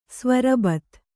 ♪ svarabat